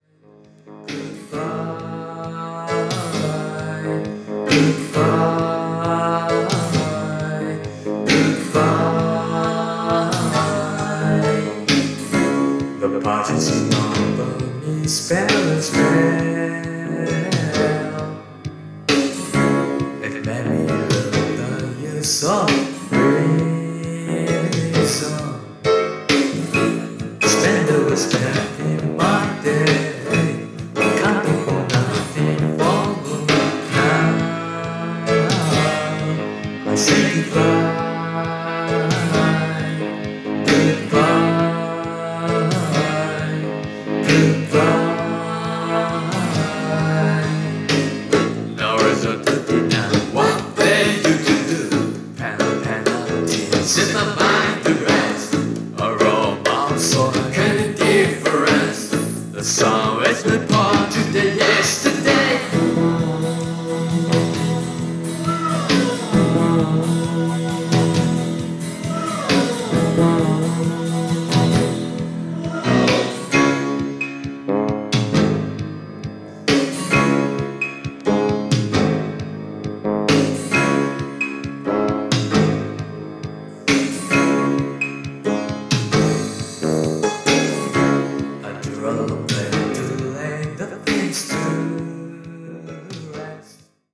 Japanese Synth-pop 12"